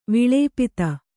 ♪ viḷēpita